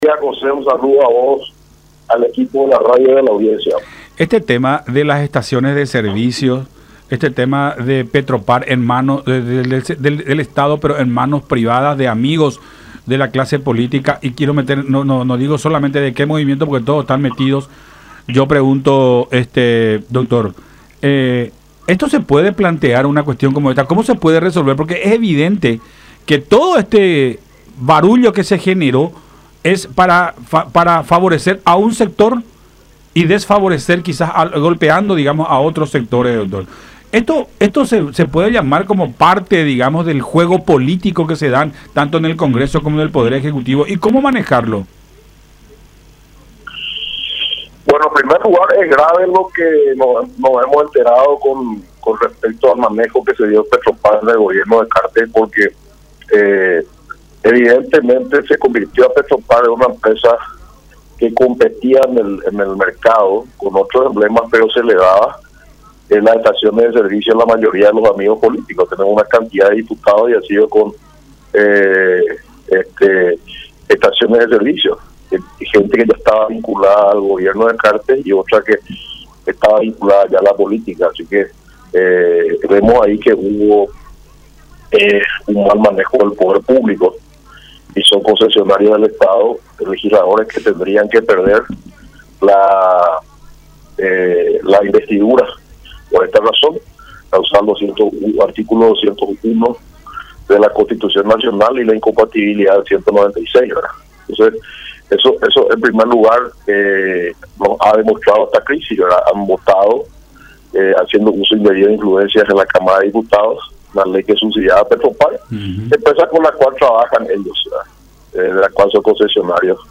en diálogo con Todas Las Voces por La Unión